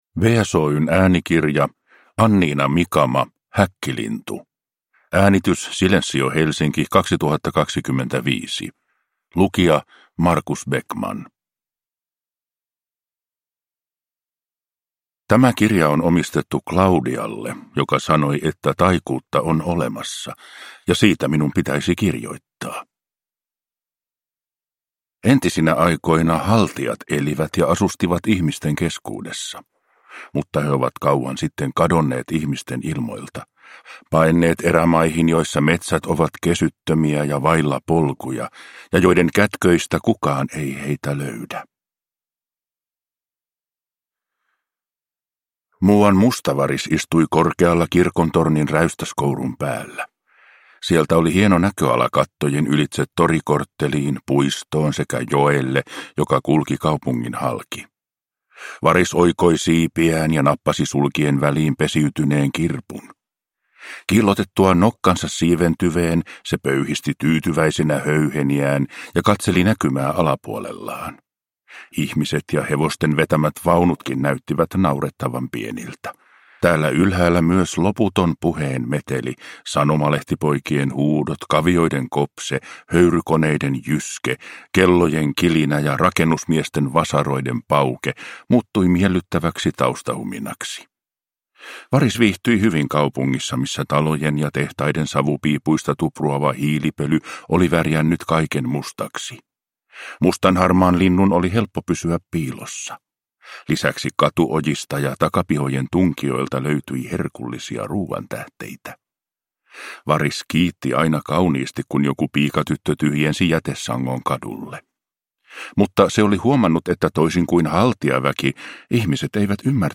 Häkkilintu – Ljudbok